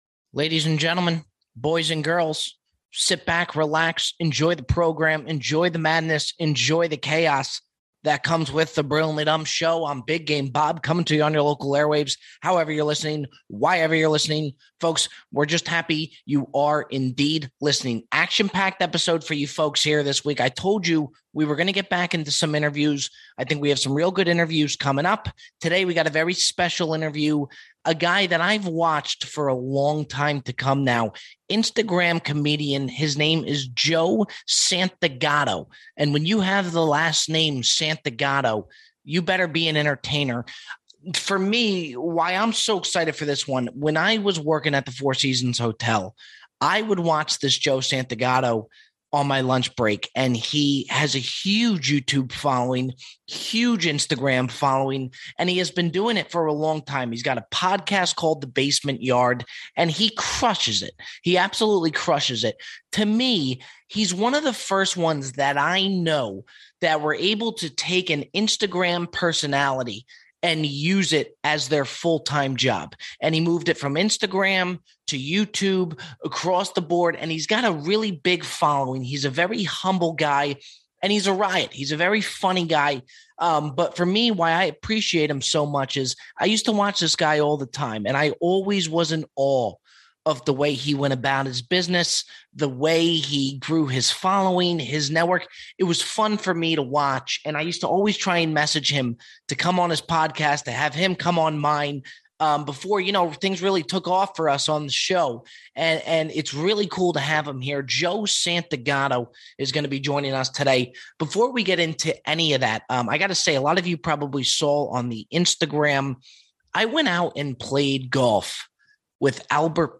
Internet pioneer Joe Santagato joins to discuss his rise to fame and the problem with buying produce at the grocery store. Voicemails close the show.